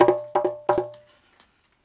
129-BONGO2.wav